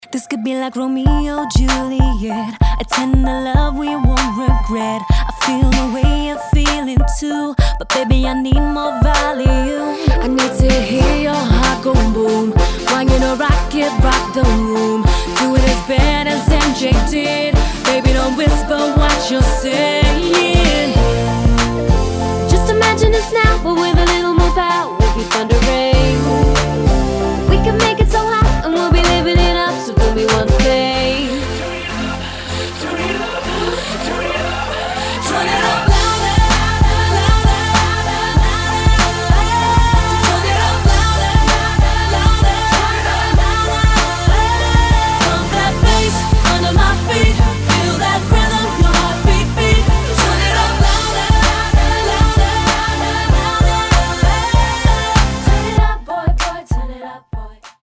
[ HOUSE / ELECTRO / DUBSTEP ]